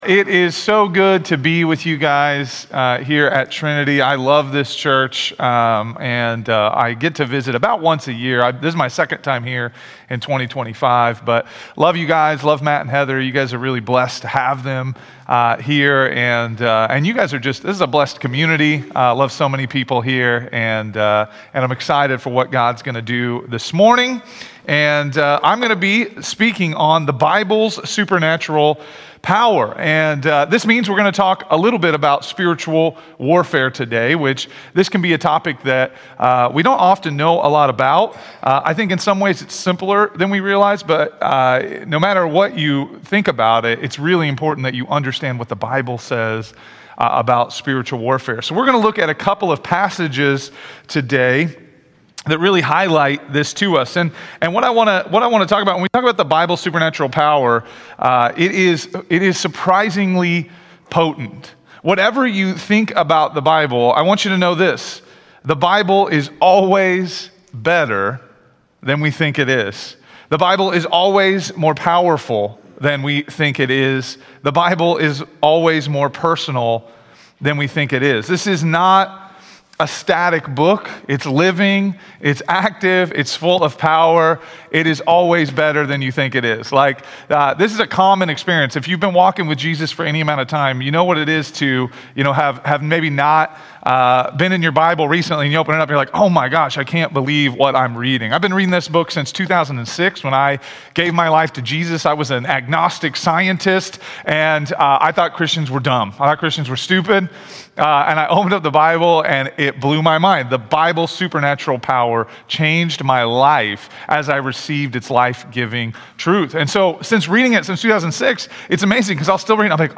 A message from the series "Bible Revival."